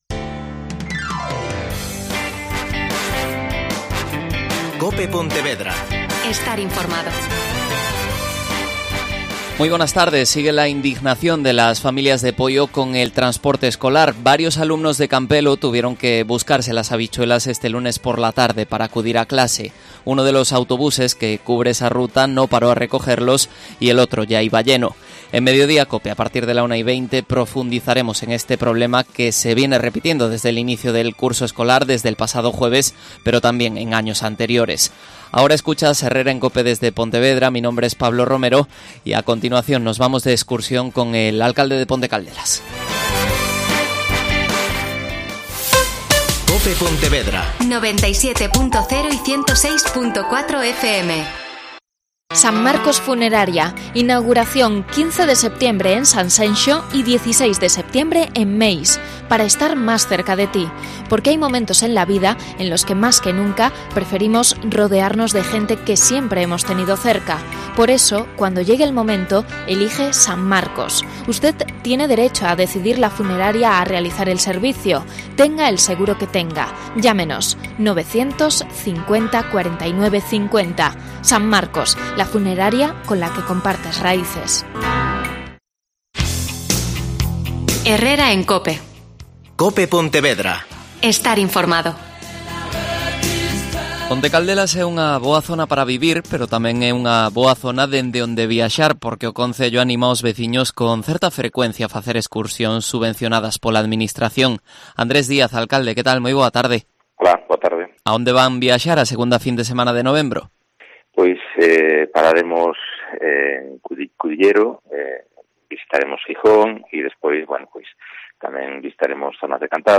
AUDIO: Andrés Díaz. Alcalde de Pontecaldelas.